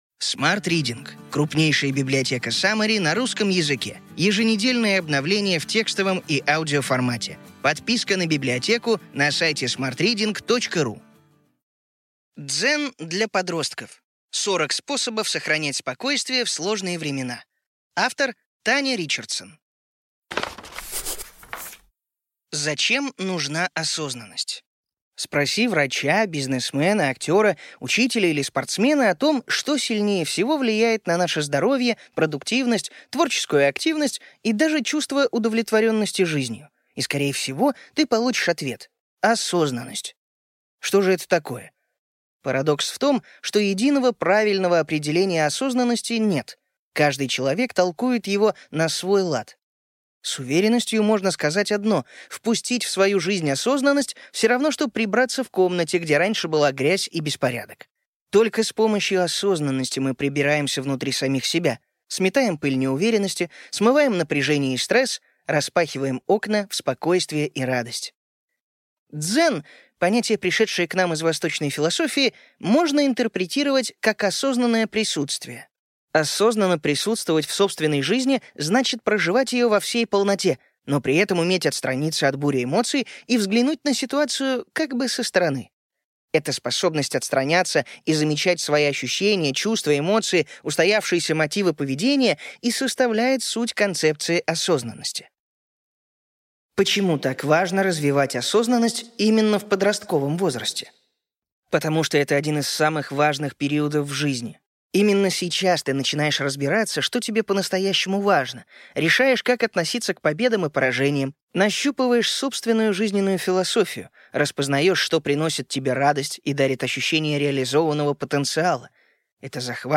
Аудиокнига Ключевые идеи книги: Дзен для подростков. 40 способов сохранять спокойствие в сложные времена.